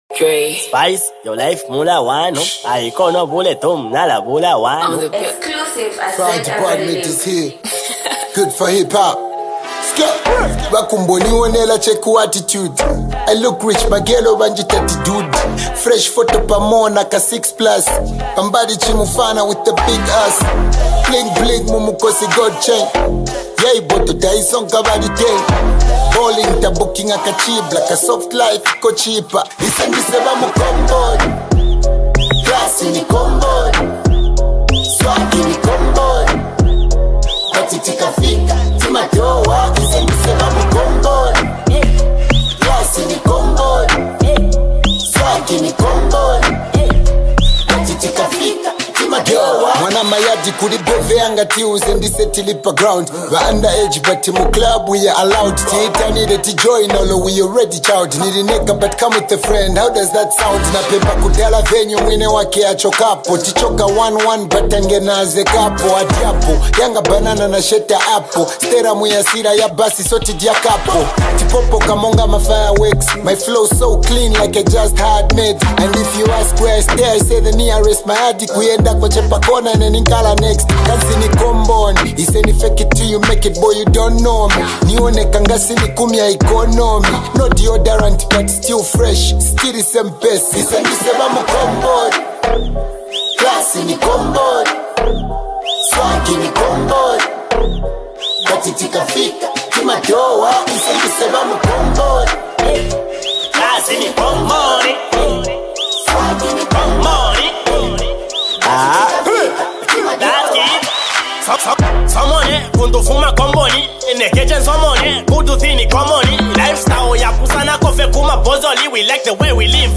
Zambian rapper